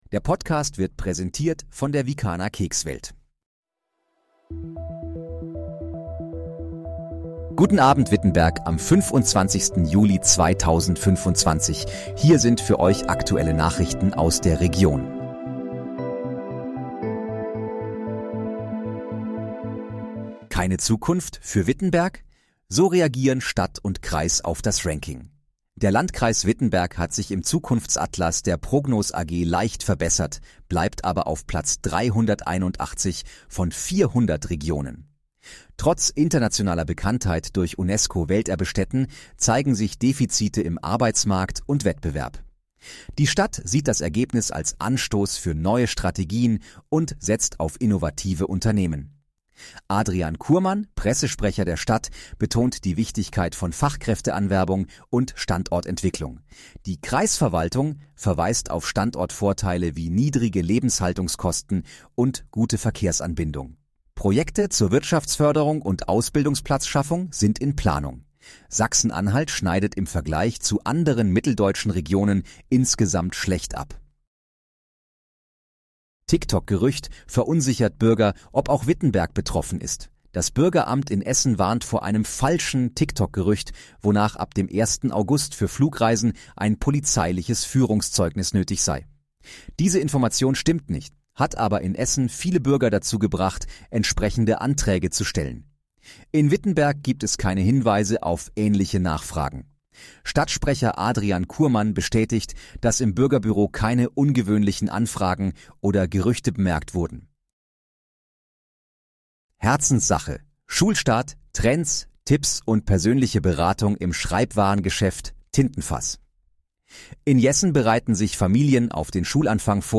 Guten Abend, Wittenberg: Aktuelle Nachrichten vom 25.07.2025, erstellt mit KI-Unterstützung
Nachrichten